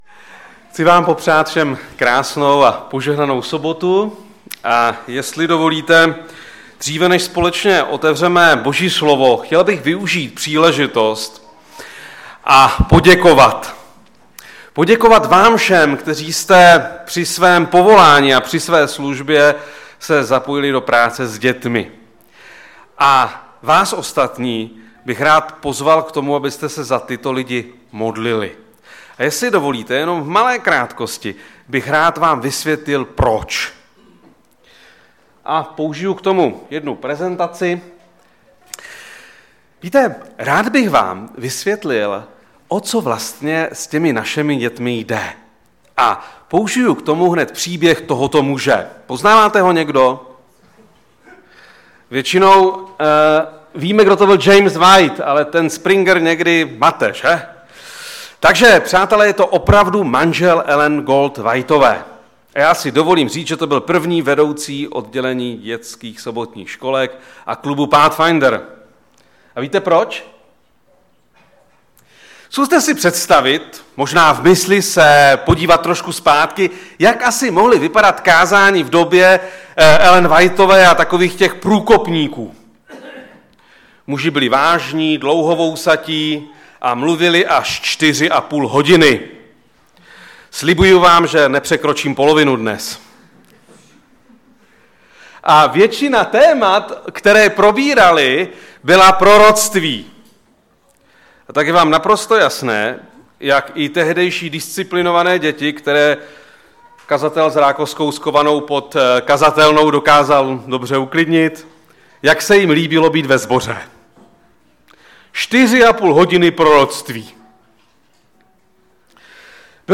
Před kázáním 3. prosince 2016 ve sboře Ostrava-Radvanice.